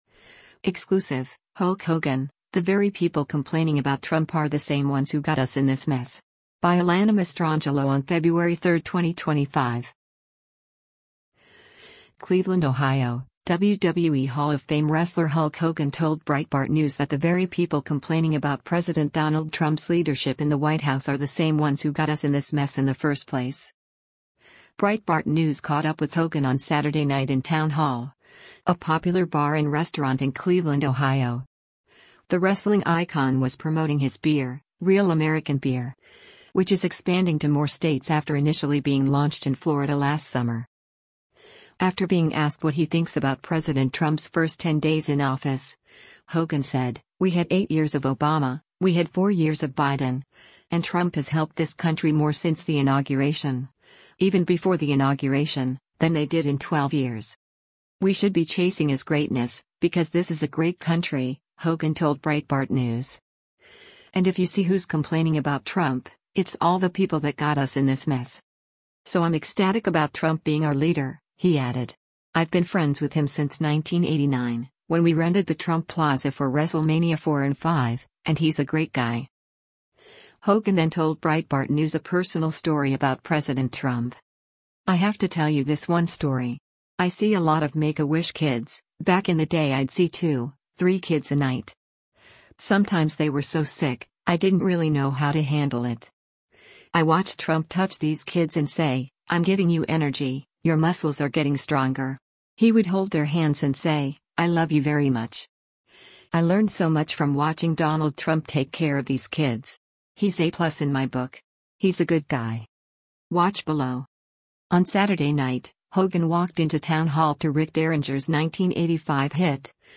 Breitbart News caught up with Hogan on Saturday night in TownHall, a popular bar and restaurant in Cleveland, Ohio.